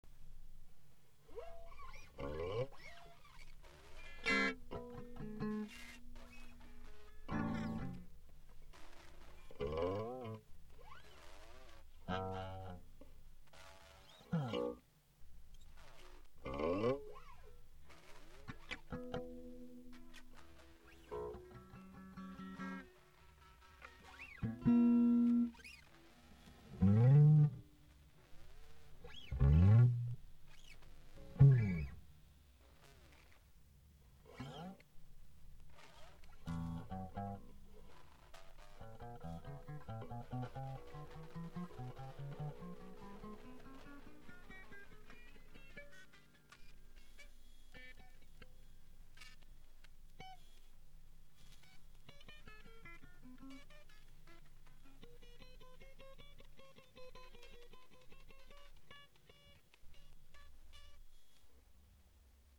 圧倒的情報量で耳が煩い、程々の音数bitで余韻じゃないんか、それでも テープェコーの
再生音は質が歪み過ぎの